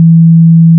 **🔊 SFX PLACEHOLDERS (23 WAV - 1.5MB):**
**⚠  NOTE:** Music/SFX are PLACEHOLDERS (simple tones)
tree_chop.wav